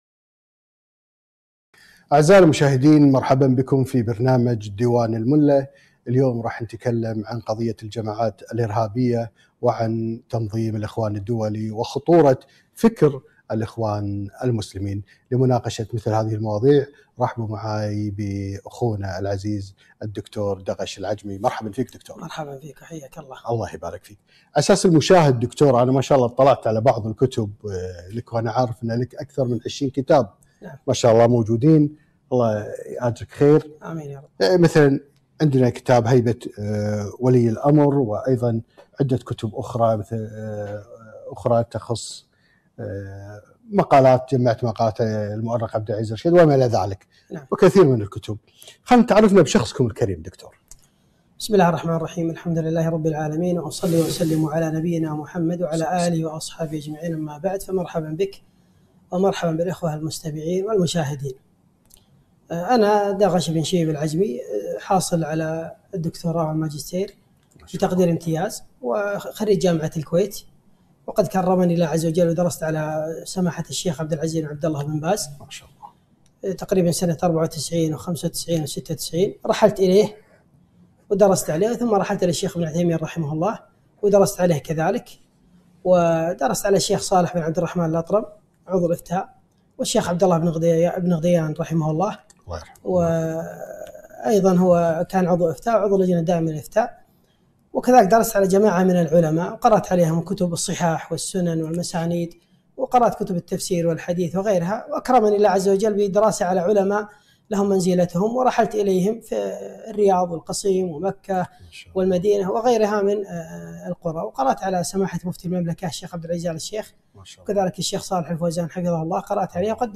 علاقة الإخوان بخيمة القذافي وتحويل آيا صوفيا لمسجد - لقاء في ديوانية الملا